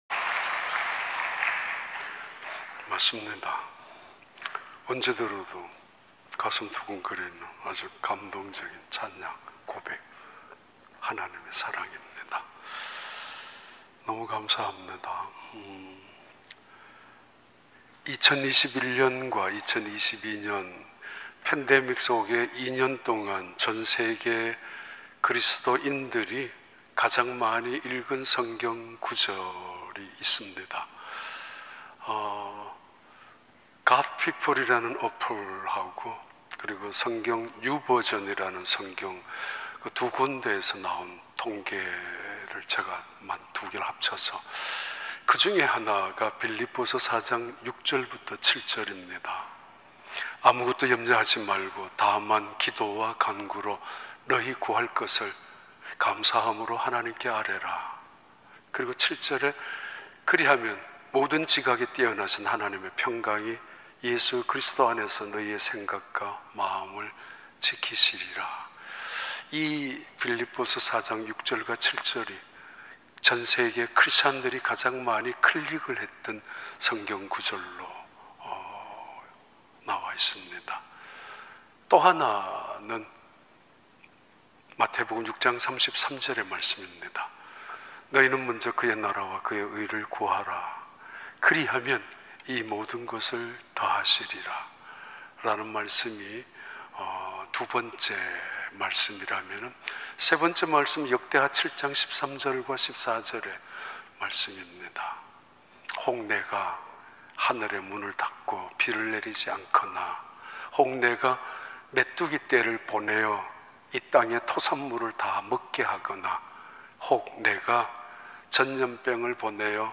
2022년 4월 24일 주일 3부 예배